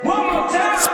TS - CHANT (12).wav